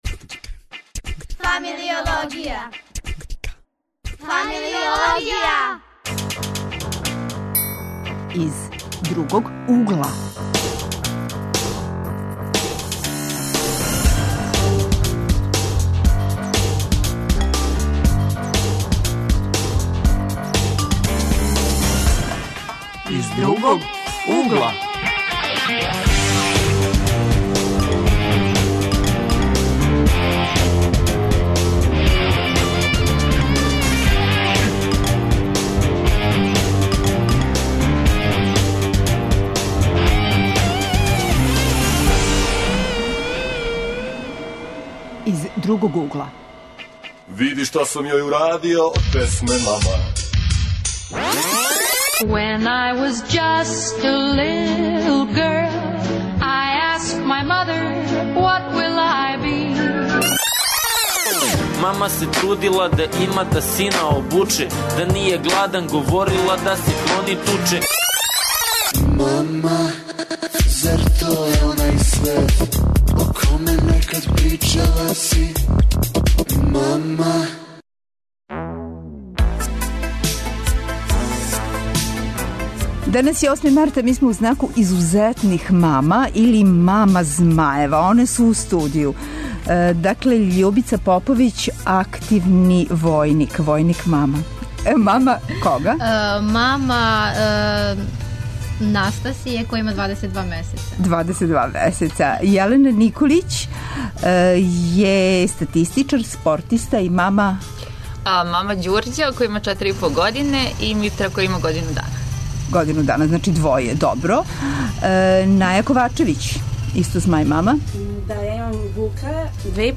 Тема: посебне младе маме - такозване 'маме змајеви'. Гости - младе маме које се баве необичним пословима или хобијем (алпинисткиња, војник, такмичарка у ринтирингу).